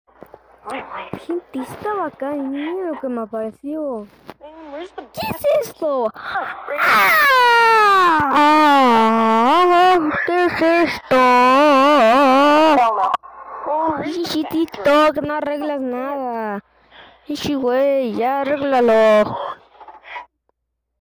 Error 404 sound effects free download